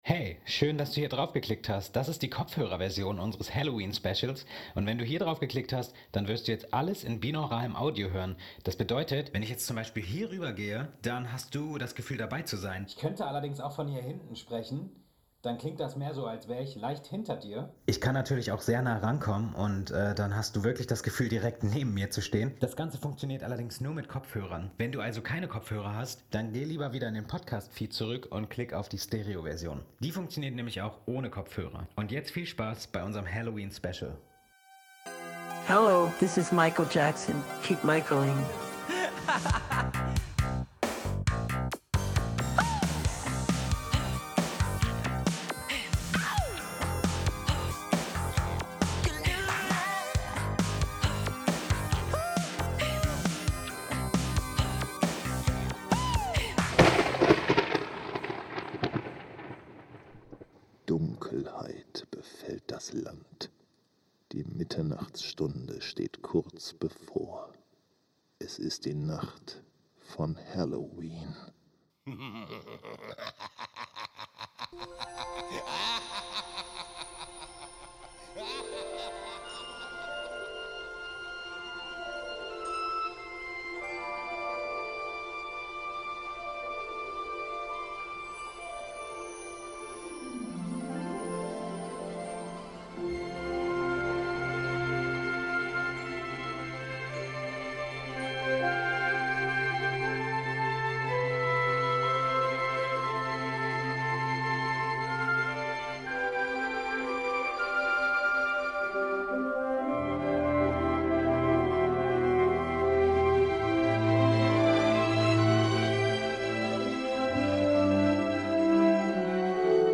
60-ein-halloweenhoerspiel-wie-kein-anderes-kopfhoererversion-mmp.mp3